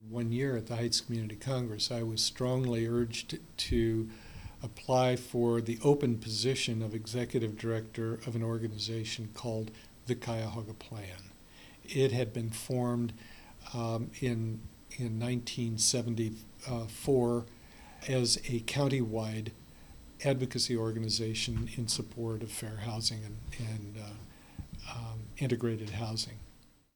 Cleveland Regional Oral History Collection